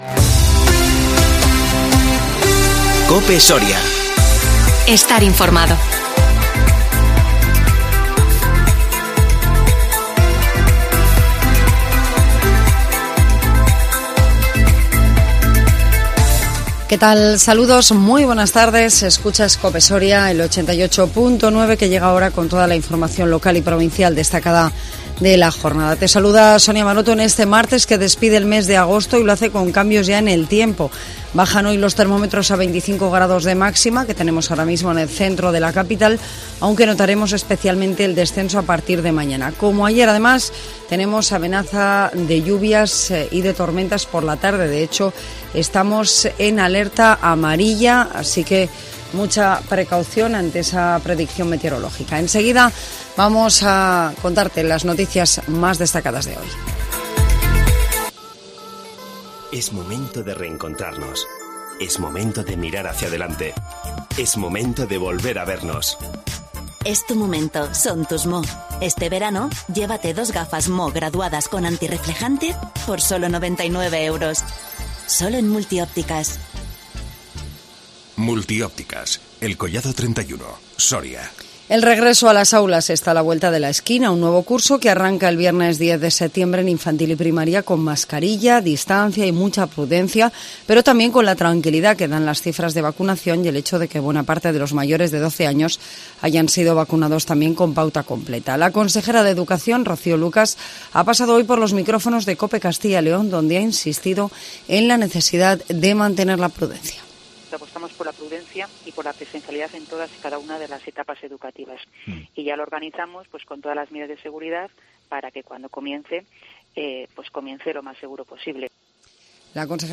INFORMATIVO MEDIODÍA COPE SORIA 31 AGOSTO 2021